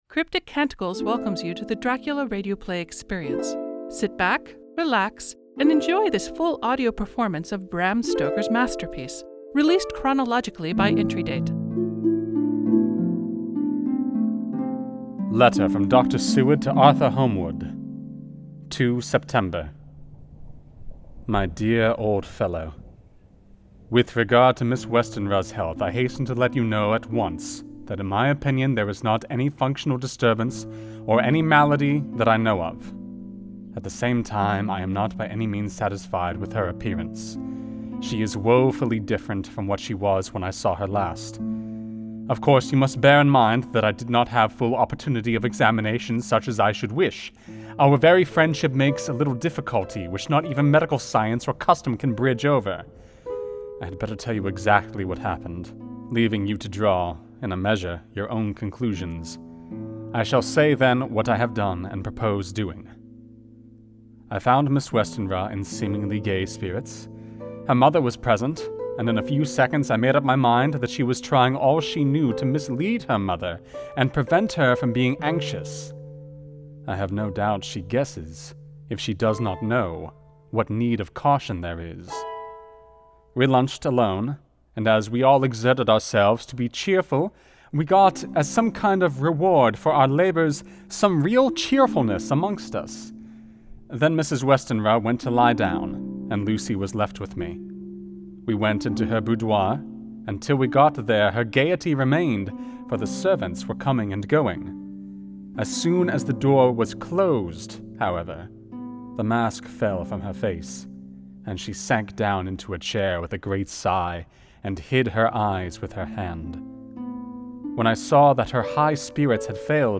Audio Engineer, SFX and Music